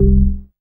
ORGAN-24.wav